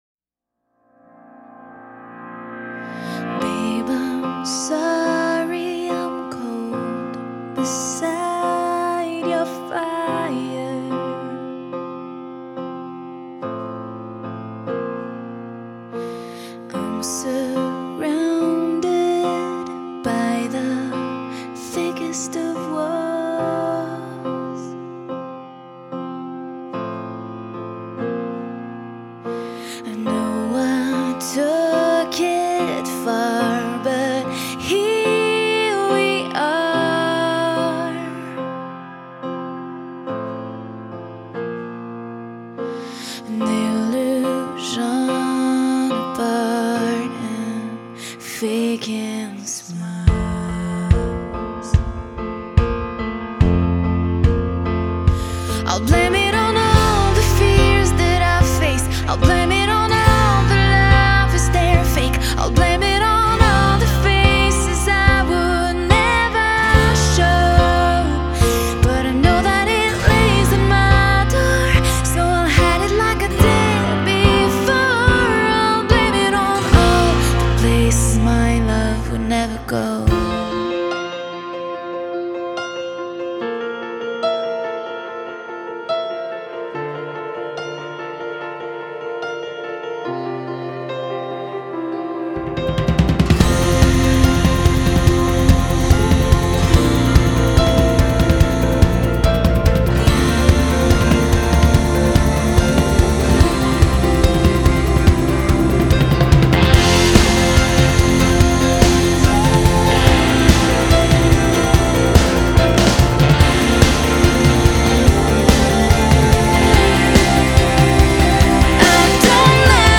Genre.........................: Pop